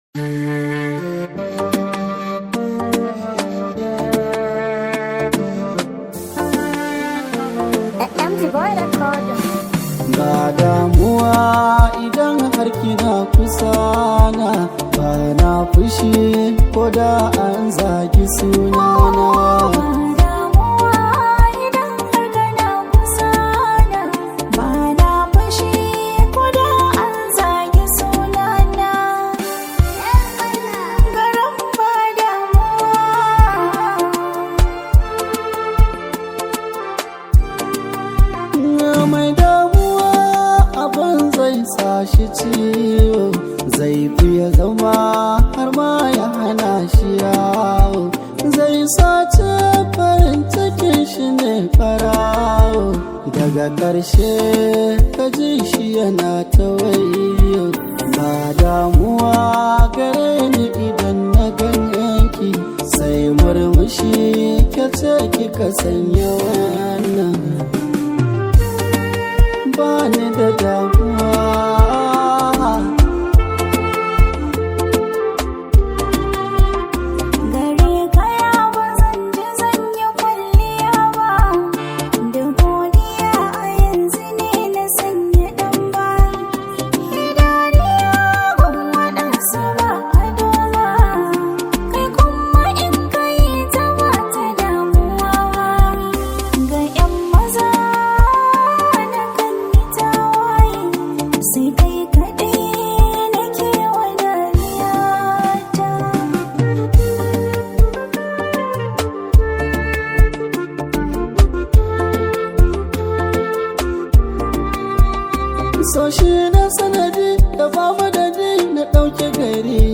an Arewa rooted song